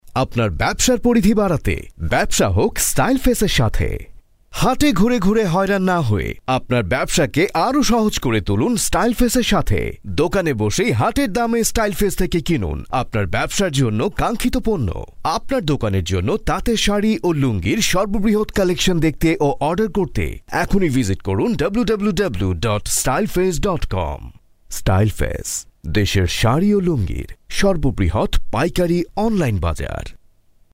男孟03 孟加拉语男声 广告干音 低沉|大气浑厚磁性|沉稳|娓娓道来|素人